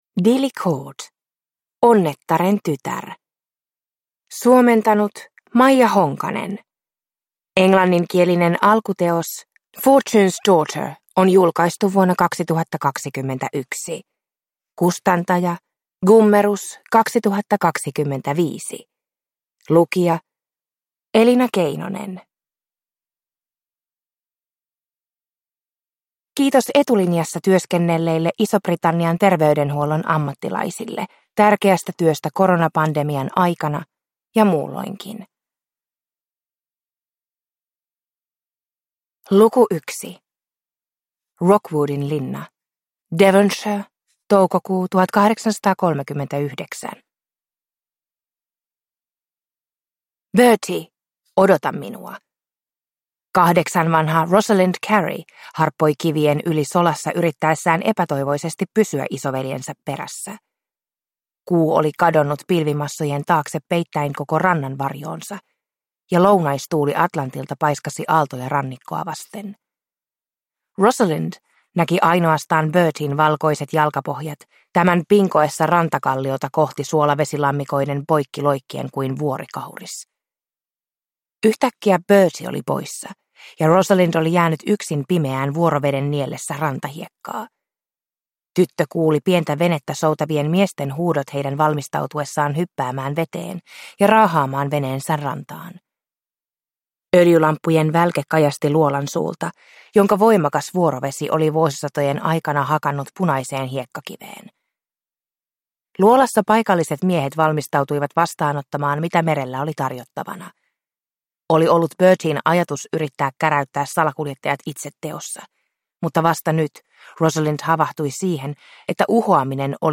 Onnettaren tytär (ljudbok) av Dilly Court